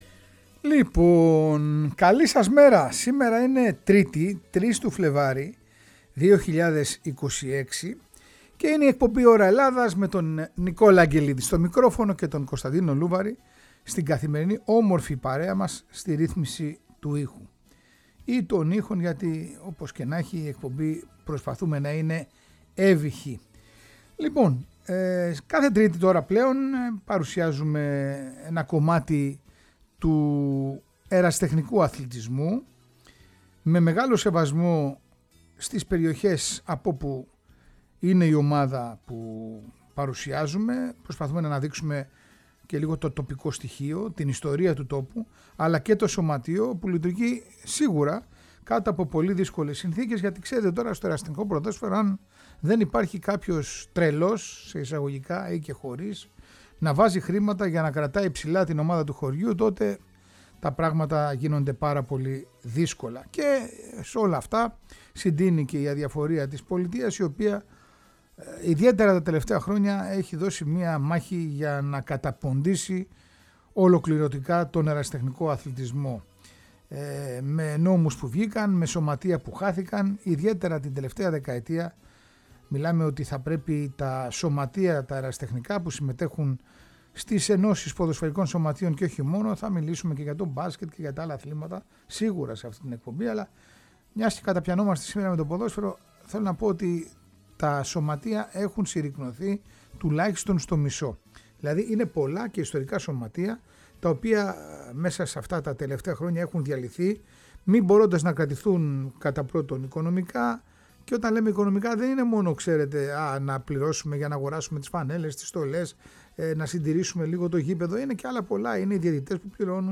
Όλα όσα πρέπει να ξέρετε για την ιστορία και τον τόπο του σωματείου που αγωνίζεται φέτος στην Β’ Κατηγορία της ΕΠΣ Κοζάνης. Ακούμε και τον καταπληκτικό ύμνο του…